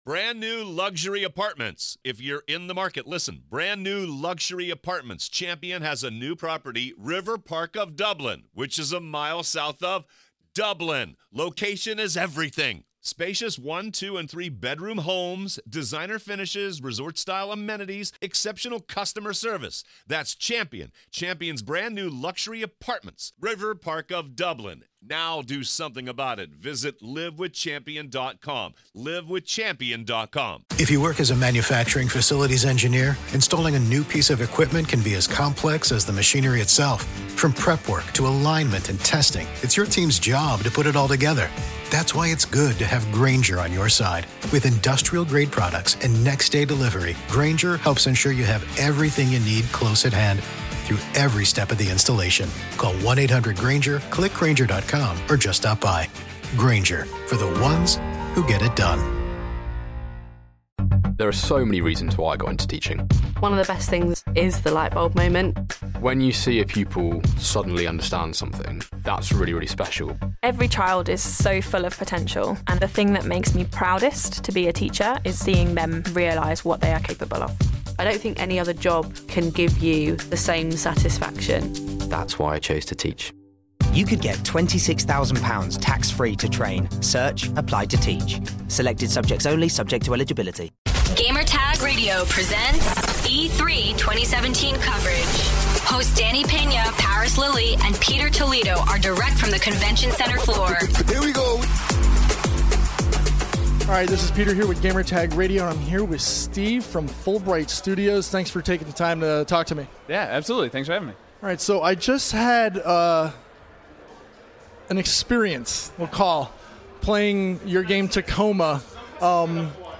E3 2017: Tacoma Interview